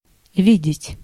Ääntäminen
US : IPA : [vjuː]